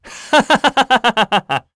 Esker-Vox_Happy3_kr.wav